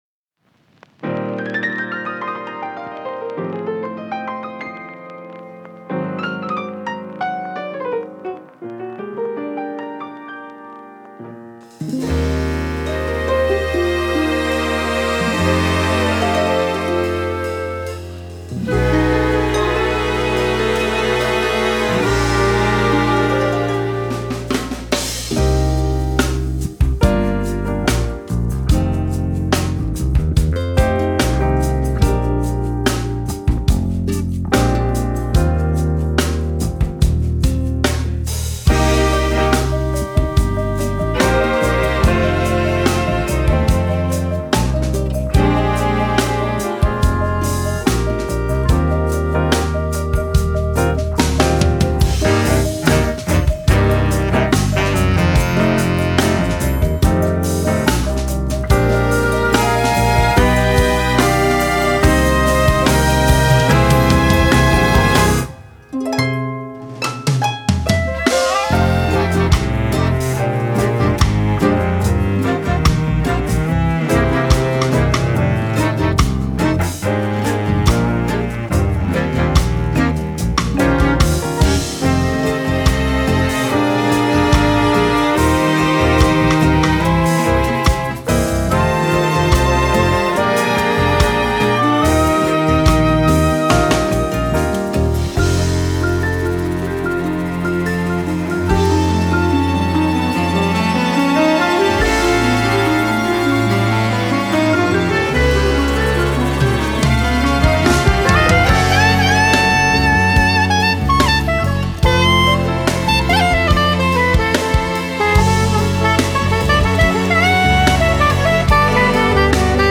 Band: Schlagzeug, Bass, Klavier, Gitarre, Perkussion
Specials: Harfe, Marimba
Streicher: Violine I, Violine II, Bratsche, Cello